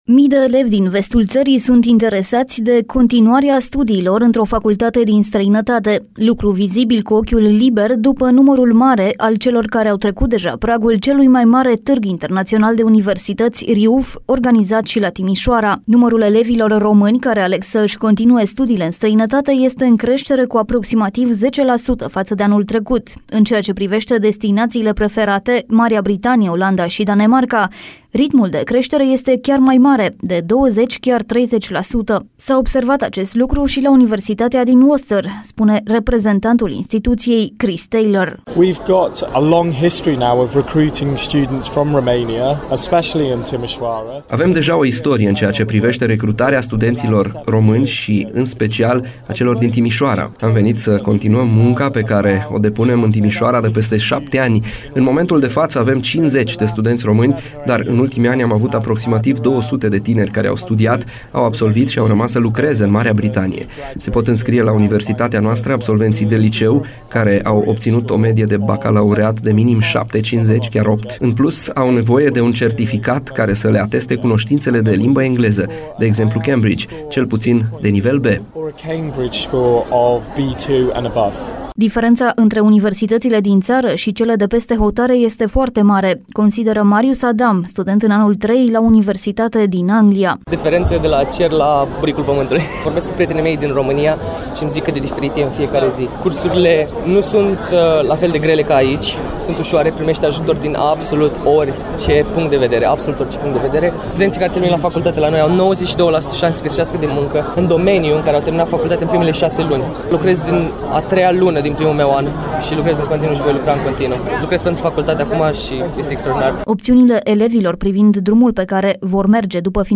A stat de vorbă cu tinerii și cu reprezentanții instituțiilor de învățământ